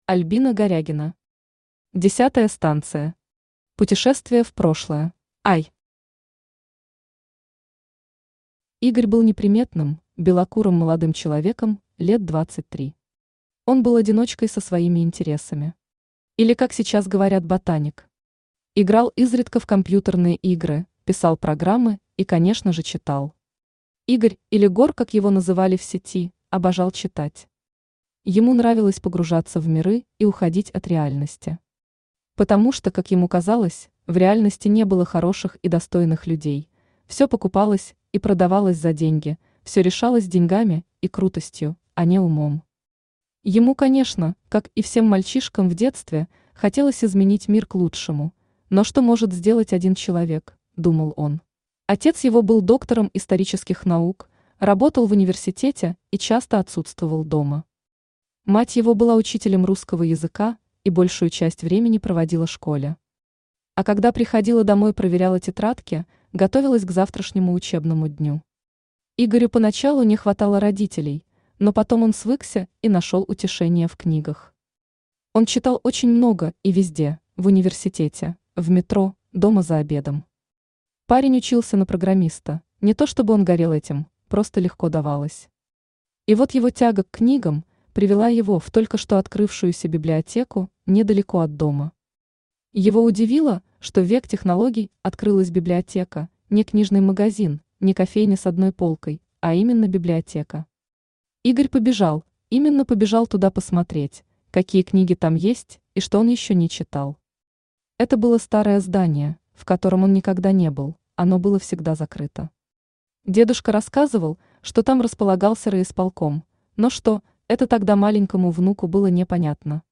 Аудиокнига Десятая станция. Путешествие в прошлое | Библиотека аудиокниг
Путешествие в прошлое Автор Альбина Горягина Читает аудиокнигу Авточтец ЛитРес.